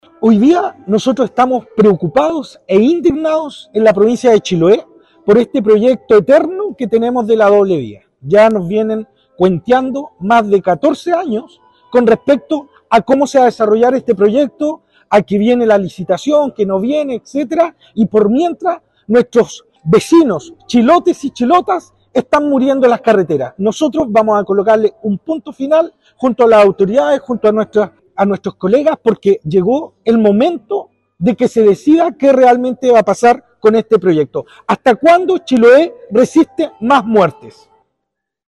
Una conferencia de prensa, ofrecieron en Puerto Montt, los consejeros regionales de la Provincia de Chiloé, para presentar sus inquietudes y su posición, con respecto al proyecto de la doble vía, que, en su primera fase, considera el tramo entre Chacao y Chonchi.